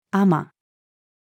尼-female.mp3